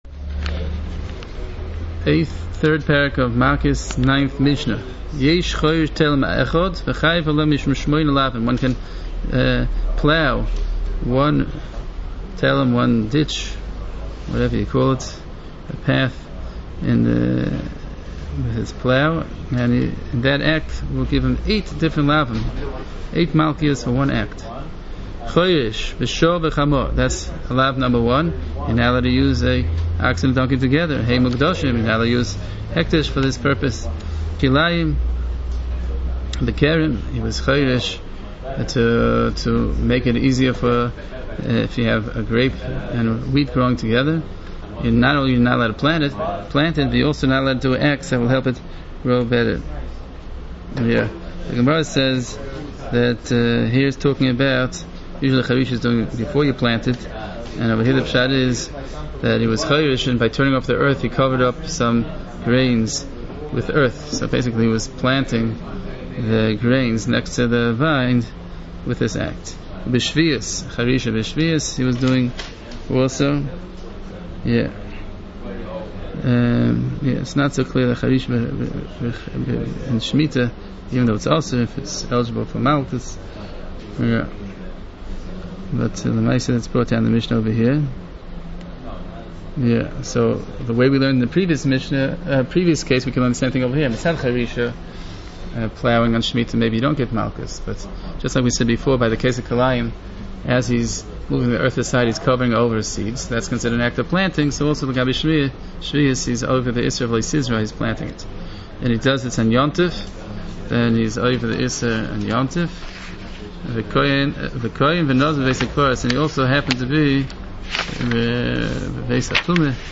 Hear the Mishnah and its Halachos